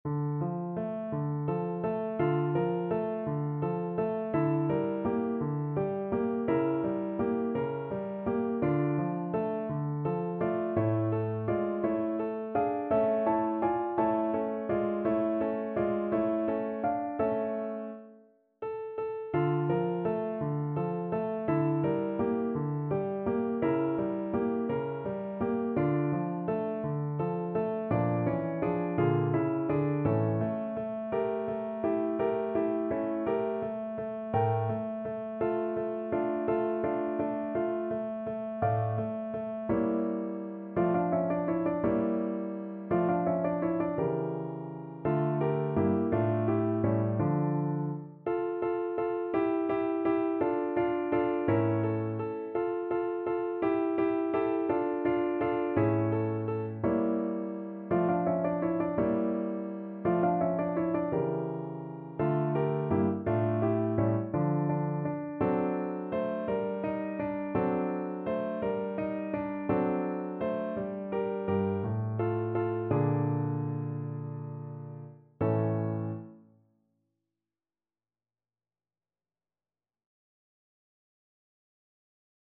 Play (or use space bar on your keyboard) Pause Music Playalong - Piano Accompaniment Playalong Band Accompaniment not yet available transpose reset tempo print settings full screen
D minor (Sounding Pitch) (View more D minor Music for Flute )
. = 56 Andante
6/8 (View more 6/8 Music)
Classical (View more Classical Flute Music)